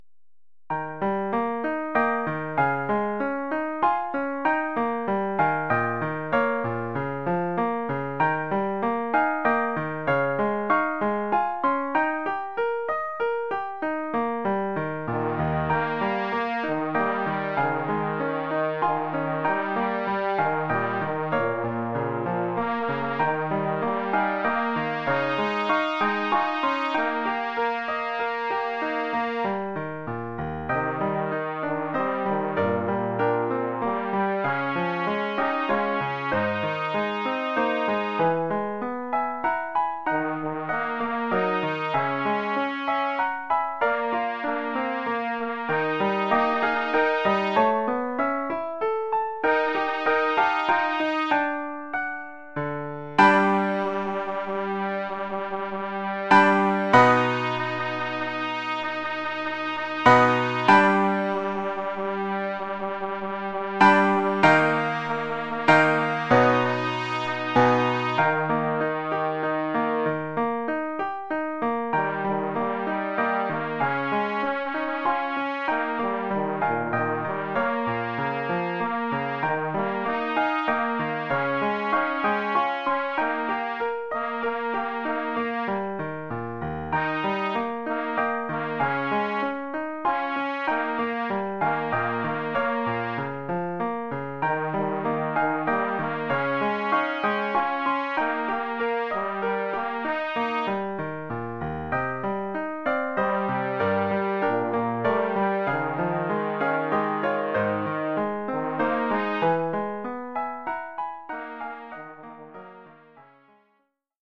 trompette basse mib et piano.